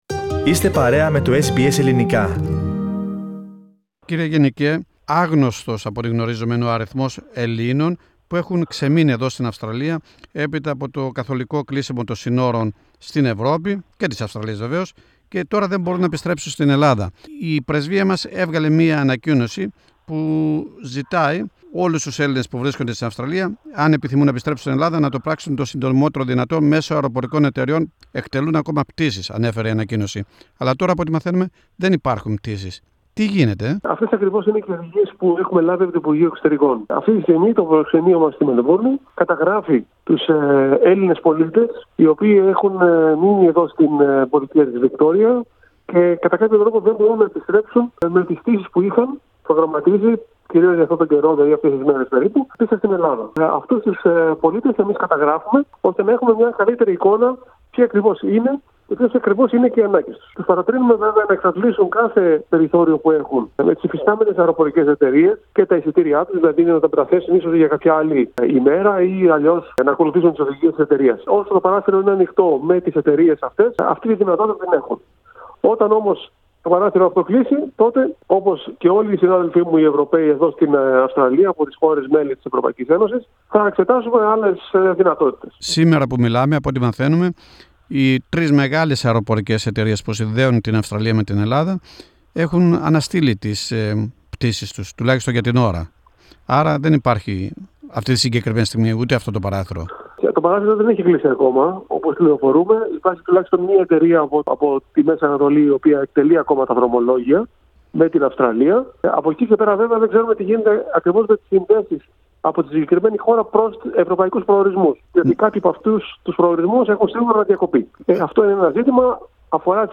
Ο γενικός πρόξενος της Ελλάδας στη Μελβούρνη, Δημήτρης Μιχαλόπουλος μιλά για τις προσπάθειες επαναπατρισμού Ελλήνων – αλλά και άλλων Ευρωπαίων πολιτών.